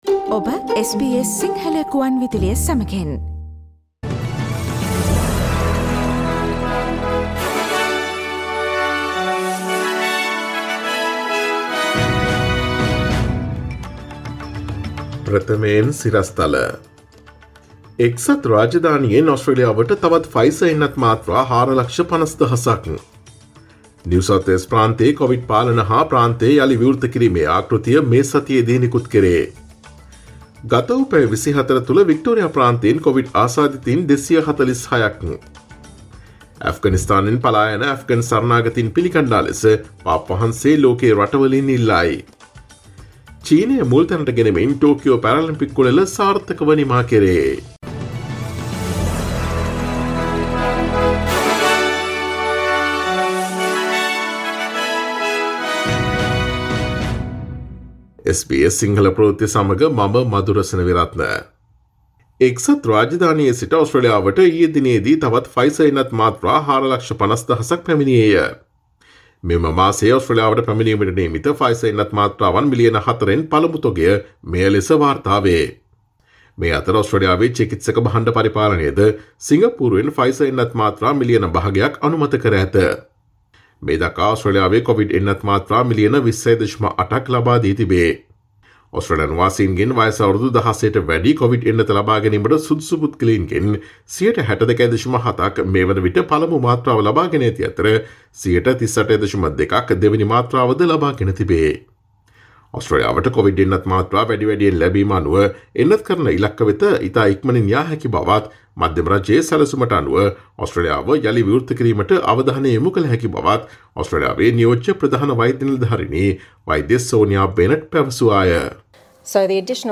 සැප්තැම්බර් 06 දා SBS සිංහල ප්‍රවෘත්ති: එක්සත් රාජධානියෙන් ඔස්ට්‍රේලියාවට තවත් Pfizer එන්නත් මාත්‍රා 450,000 ක්
ඔස්ට්‍රේලියාවේ නවතම පුවත් මෙන්ම විදෙස් පුවත් සහ ක්‍රීඩා පුවත් රැගත් SBS සිංහල සේවයේ 2021 සැප්තැම්බර් 06 වන දා සඳුදා වැඩසටහනේ ප්‍රවෘත්ති ප්‍රකාශයට සවන් දීමට ඉහත ඡායාරූපය මත ඇති speaker සලකුණ මත click කරන්න.